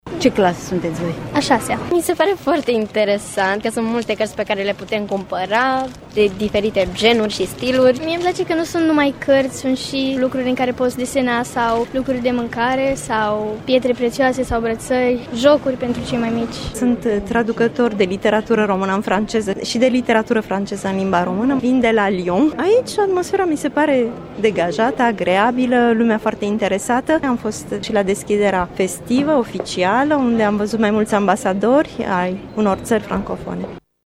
stiri-19-nov-vox-gaudeamus.mp3